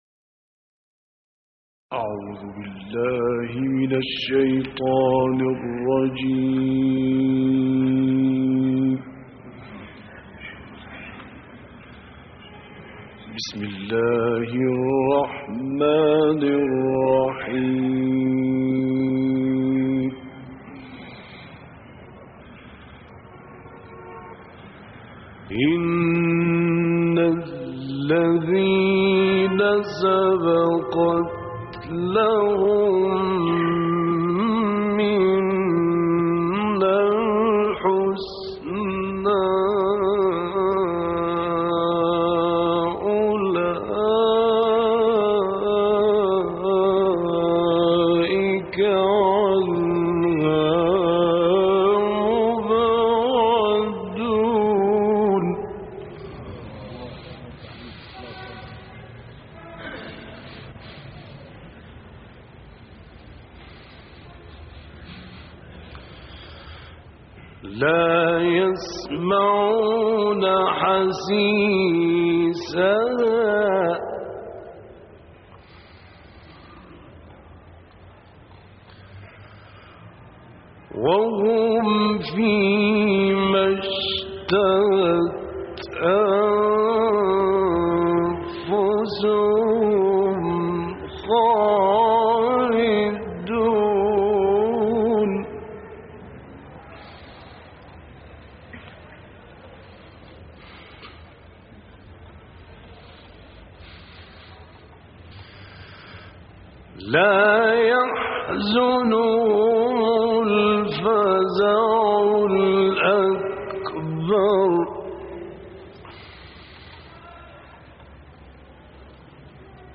دانلود قرائت سوره های انبیا آیات 101 تا آخر ، شمس و نصر - استاد راغب مصطفی غلوش